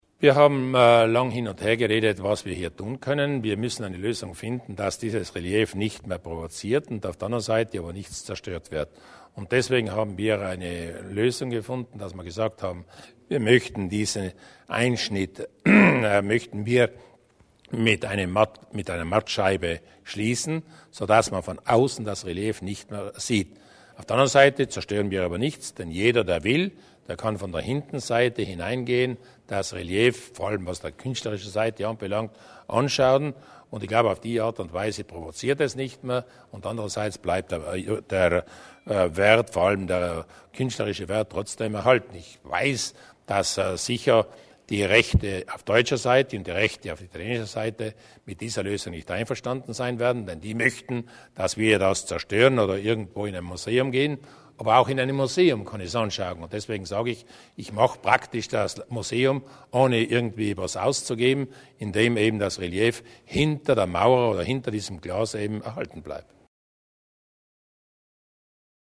Landeshauptmann Durnwalder zum Mussolini-Relief am Bozner Finanzpalast